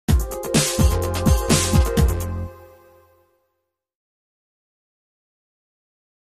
Music Logo; Short Dance Groove Beat, With A Mysterious Feel.